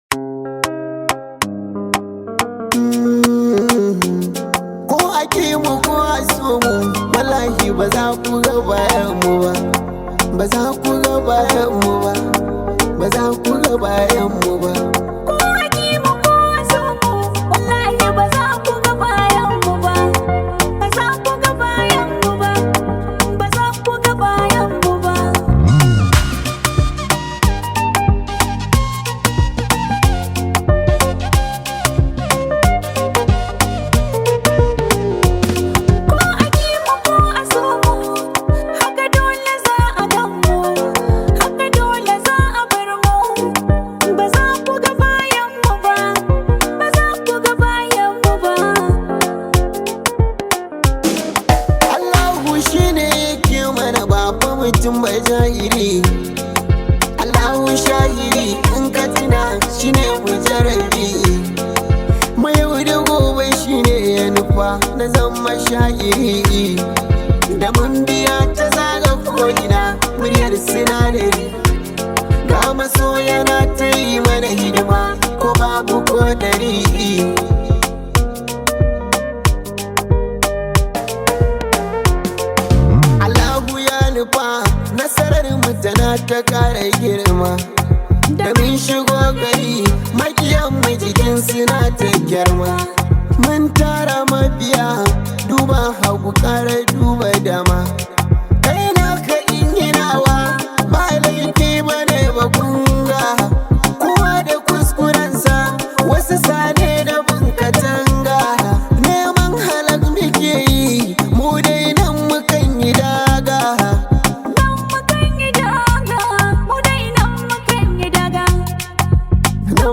hausa song
This high vibe hausa song